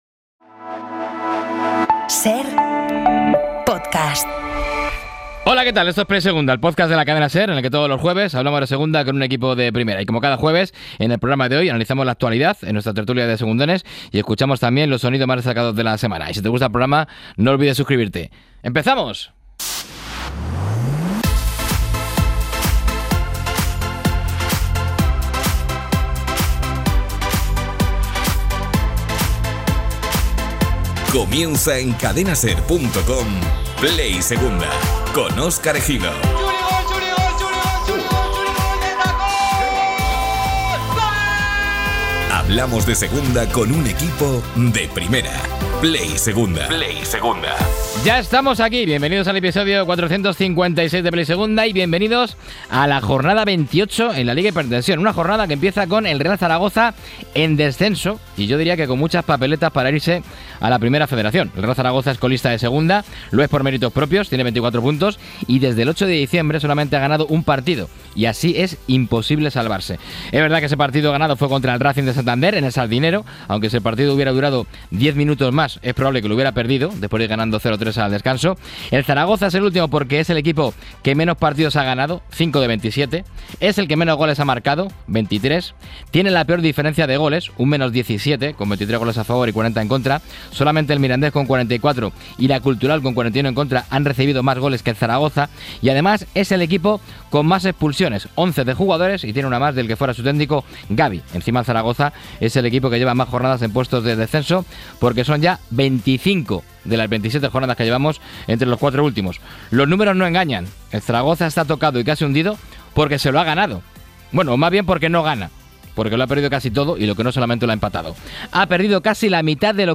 La compra de Cristiano del 25% del Almería y con el Castellón-Racing en la tertulia